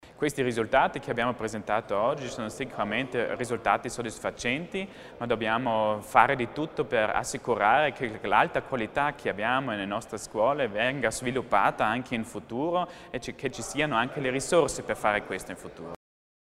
L'Assessore Mussner spiega lo stato di salute della scuola ladina
Si è svolta questa mattina (11 luglio) a Palazzo Widmann la conferenza stampa congiunta degli assessori provinciali alla scuola per un bilancio dell’anno scolastico appena concluso.